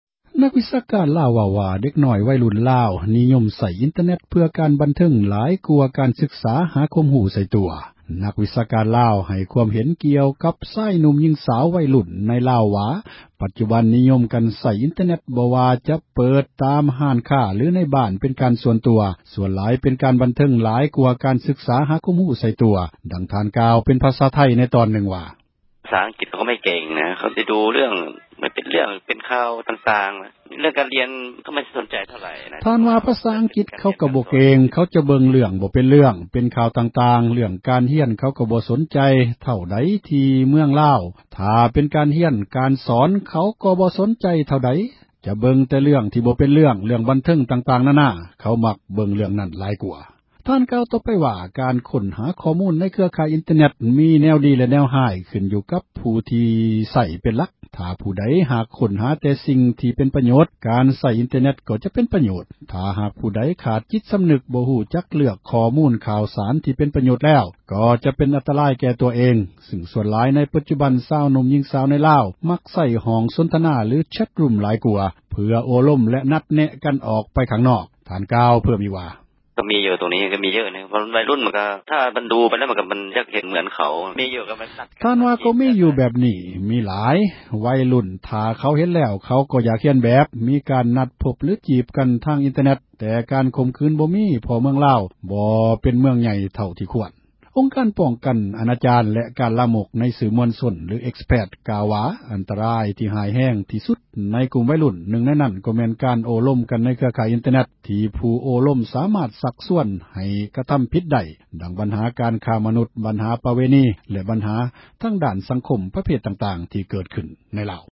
ດັ່ງທ່ານໄດ້ຊີ້ແຈງ ແລະ ເວົ້າເປັນສໍານຽງໄທ ໃນຕອນນື່ງວ່າ: